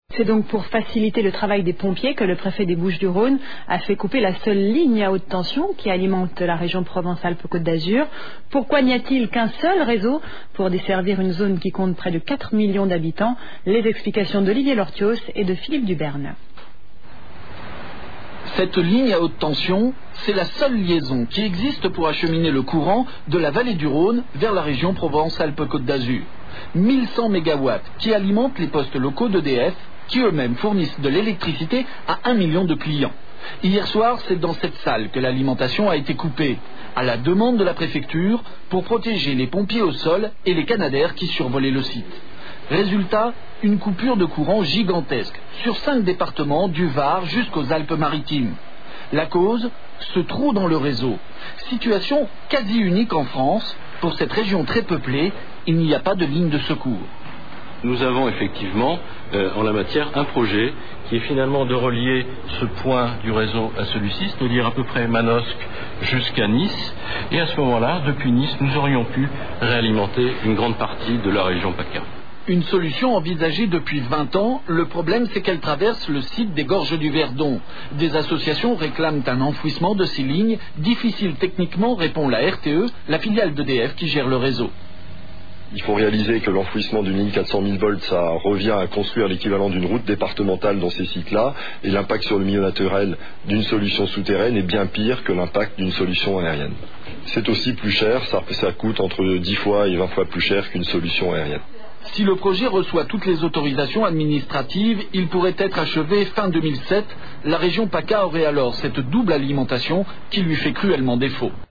Reportage diffusé sur TF1 au journal de 20h le samedi 07 mai 2005
Bande-son du reportage